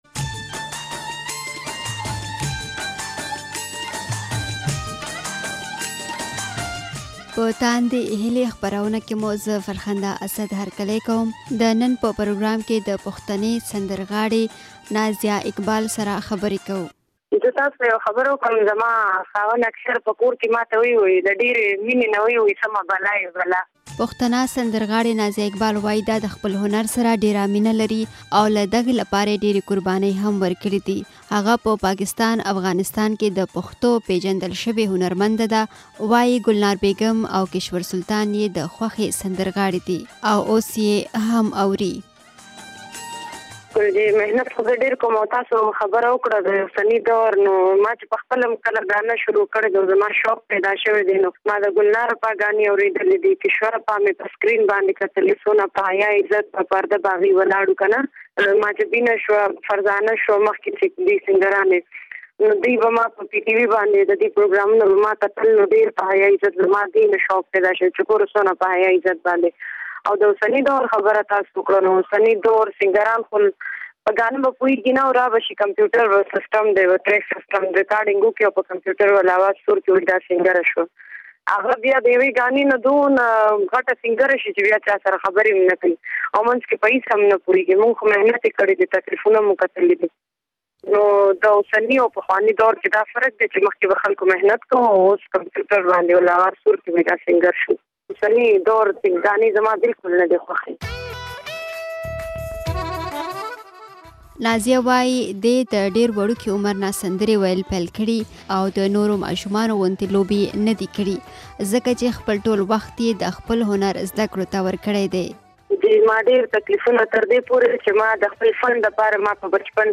دا ځل مو په خپرونه کې له نومیالۍ سندرغاړې نازیه اقبال سره خبرې کړې دي. نوموړې وايي، اوسني ډېری سندرغاړي کمپیوټري سندرې وايي او اصلاً د سندرې ویلو هنر یې نه دی زده. د دې په خبره، له وړوکتوبه یې سندرې ویل پیل کړې دي او ډېره قرباني یې ورکړې ده.